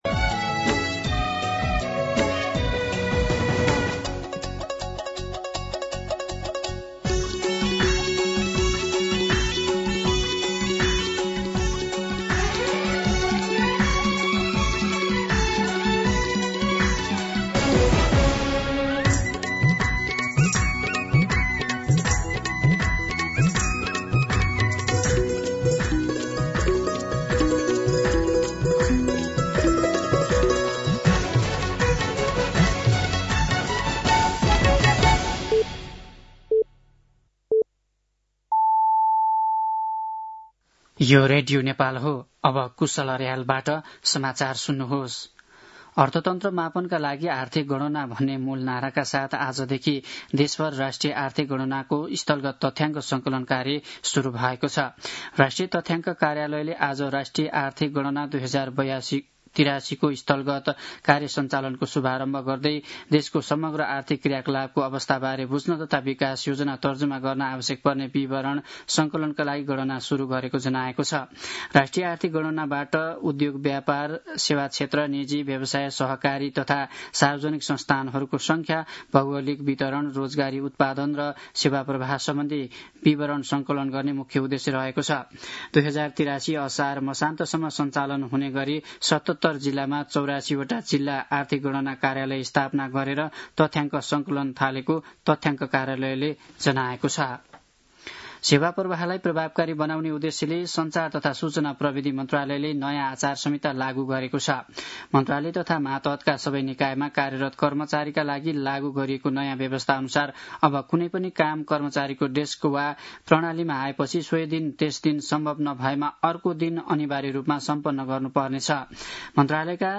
An online outlet of Nepal's national radio broadcaster
दिउँसो ४ बजेको नेपाली समाचार : २ वैशाख , २०८३